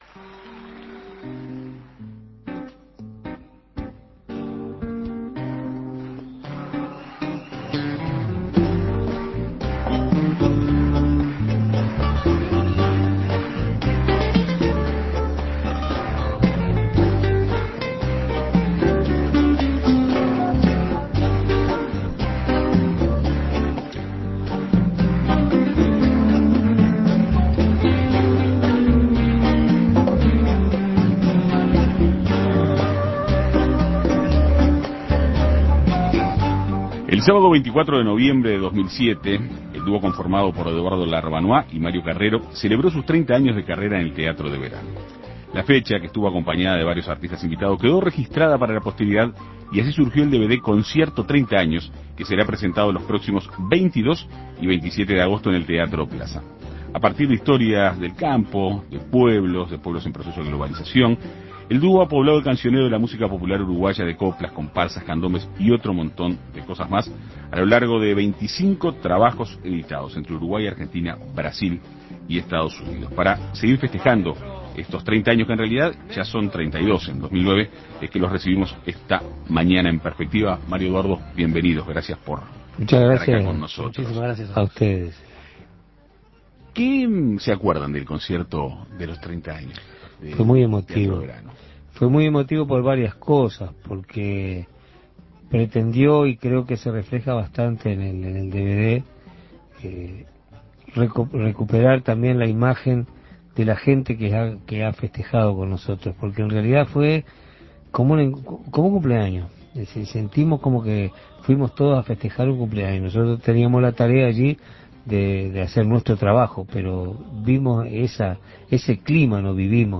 Para sumarse a la celebración, En Perspectiva Segunda Mañana dialogó con los artistas.